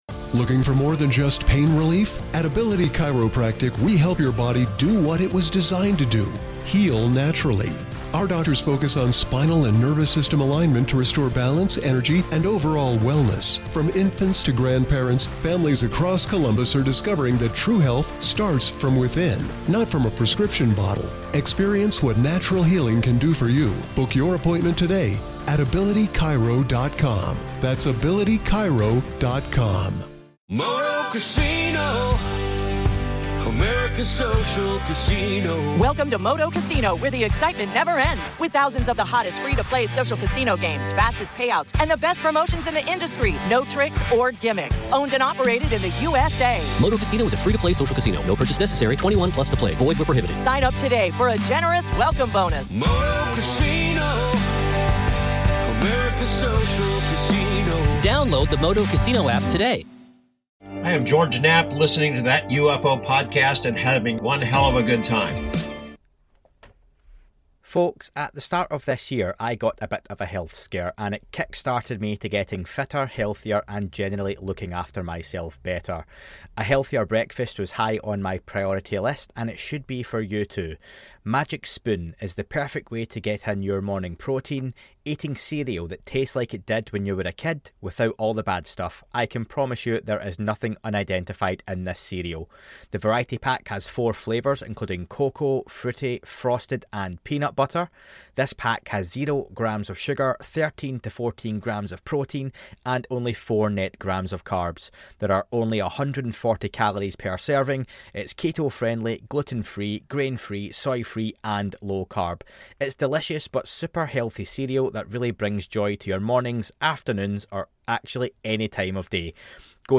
*Warning that some explicit language is used*